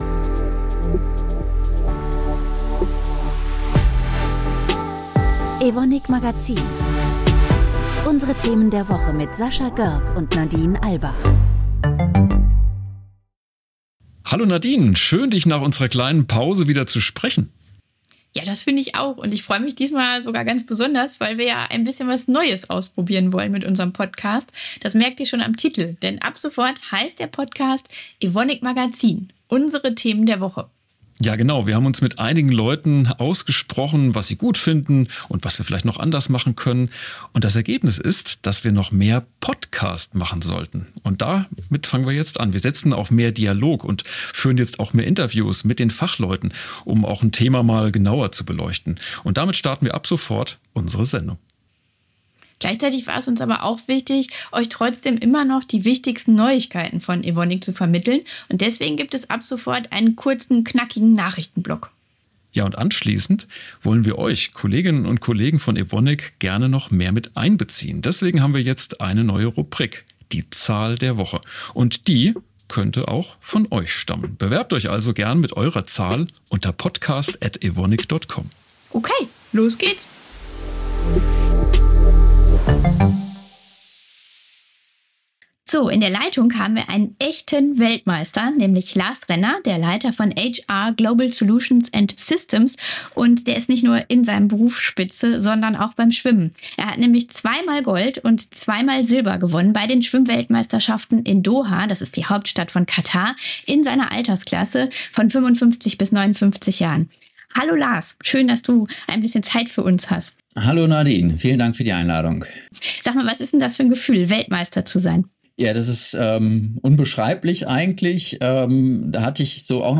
Interview
Nachrichten